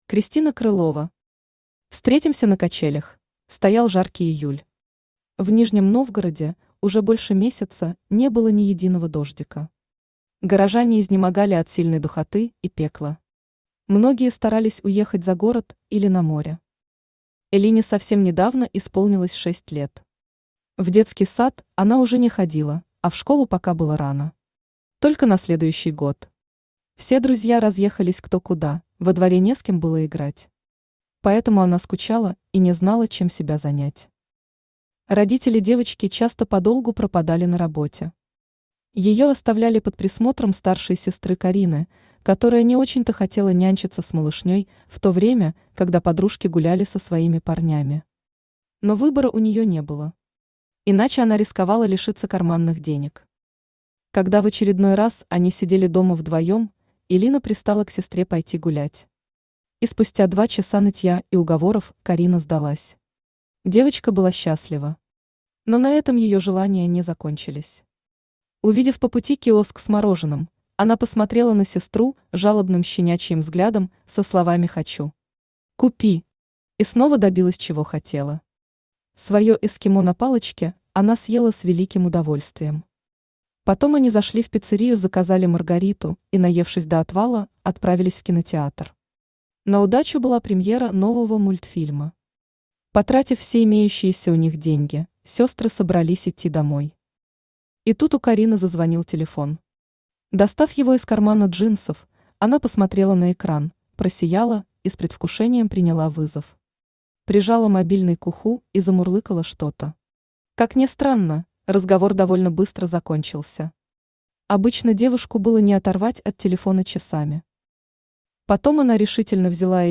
Аудиокнига Встретимся на качелях!
Автор Кристина Крылова Читает аудиокнигу Авточтец ЛитРес.